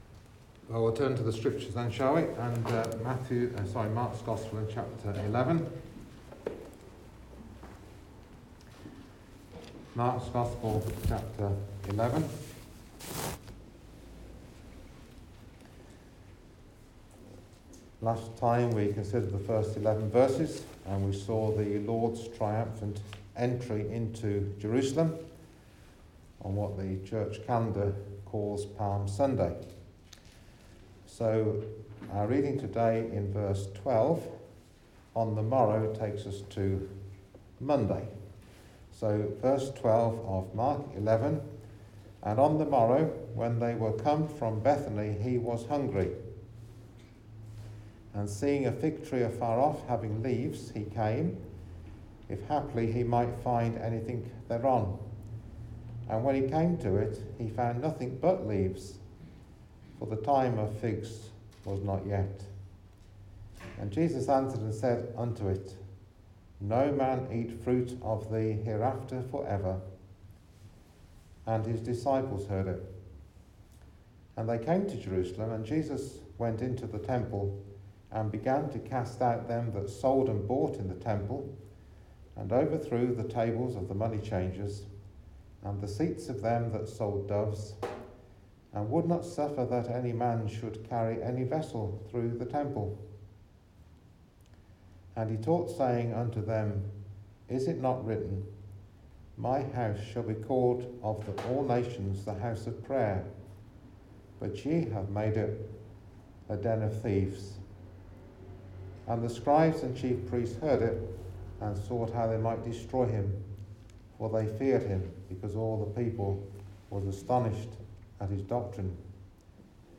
Wednesday Bible Reading – Mark 11:27-33
Mark 11:27-33 Service Type: Ministry